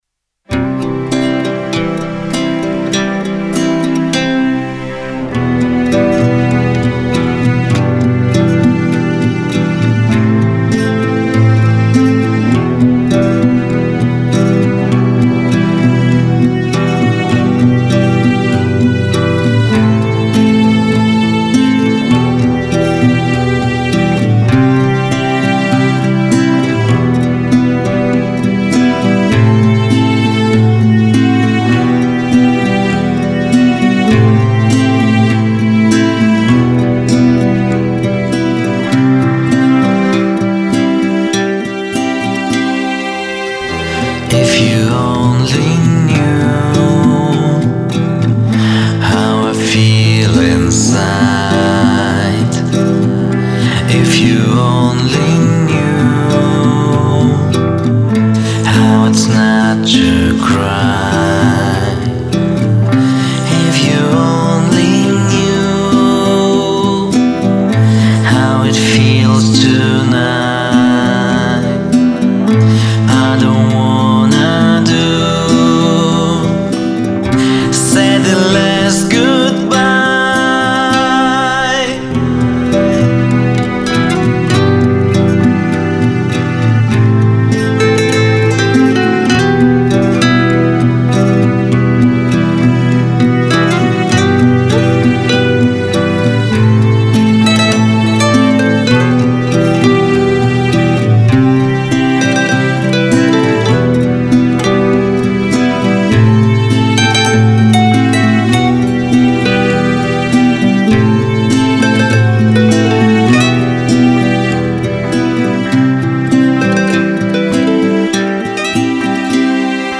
A very short song, recorded several years ago...
sad
guitar
drama